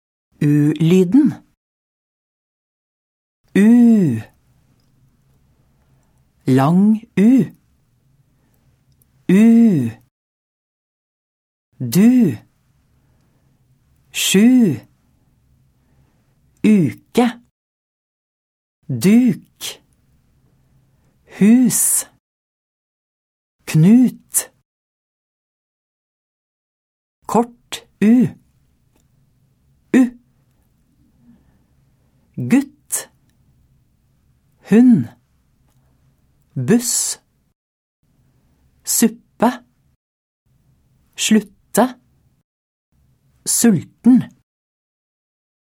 Uttale: U-lyden (s. 64-65)